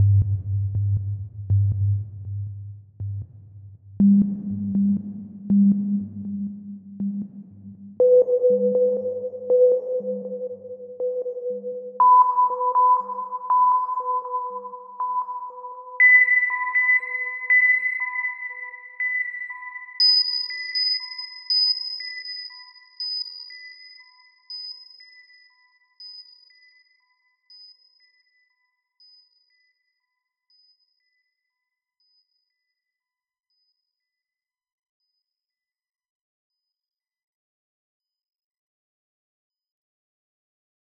Atlas - STest1-PitchPulse-100,200,500,1000,2000,5000.flac